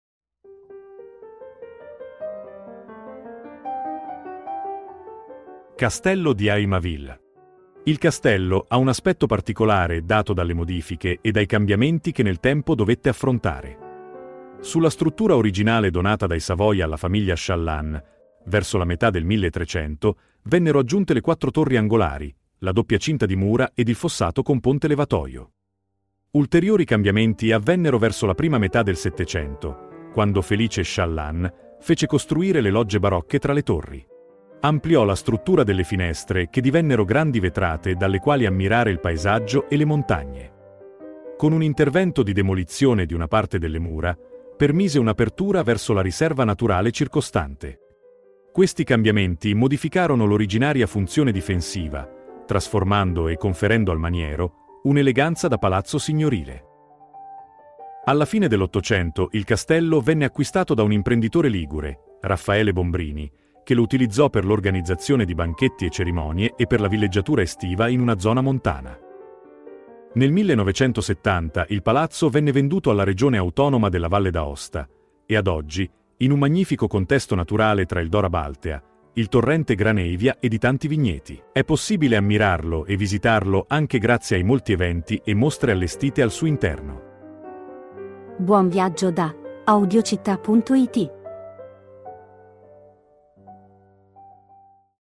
Audioguida – Il Castello di Aymavilles